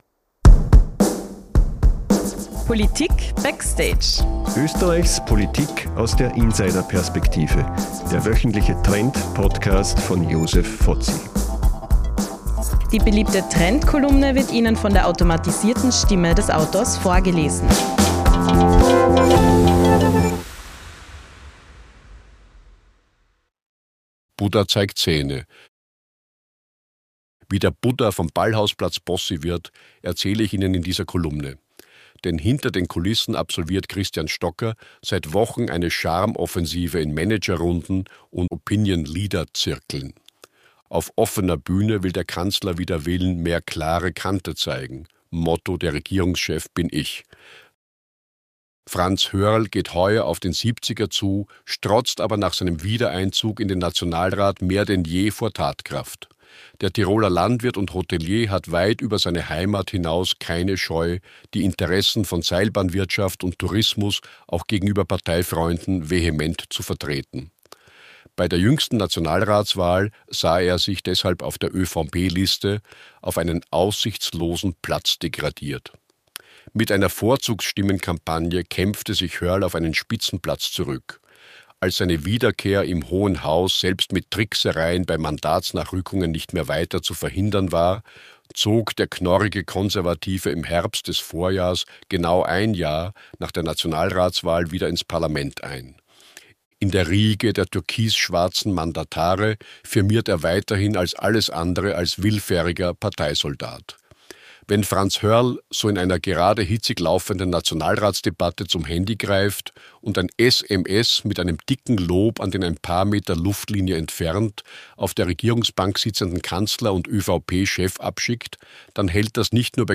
Wie jede Woche erzählt Ihnen die KI-generierte Stimme von